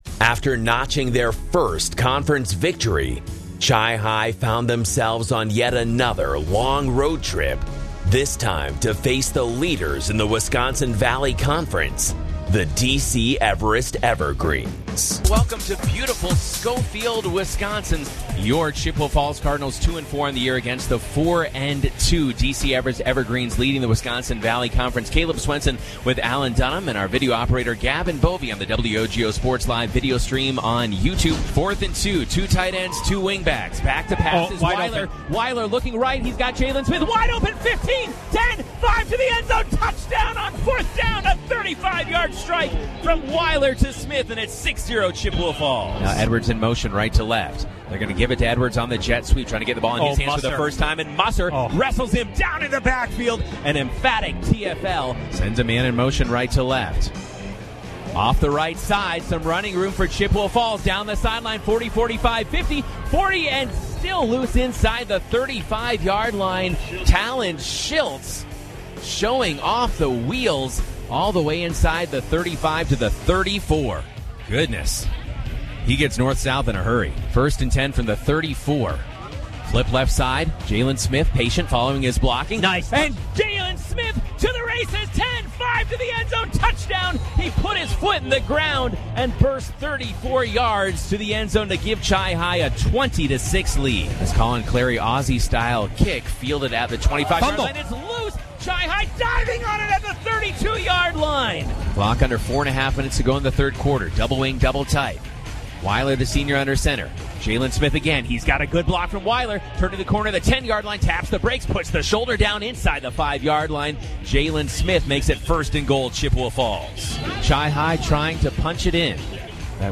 At Dorais Field for the regular season home finale, the Chippewa Falls Cardinals (3-5, 2-4 Big Rivers Conference) moved the ball on their first drive, but punted in plus territory, giving way to a Hudson offense that promptly began a lengthy drive of their own, capped off with the game's first score.